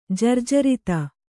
♪ jarjarita